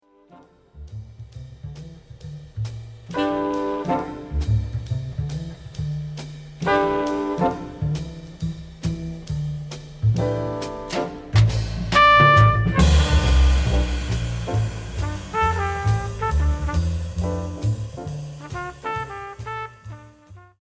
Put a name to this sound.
The Fill: